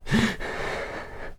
Player_UI [35].wav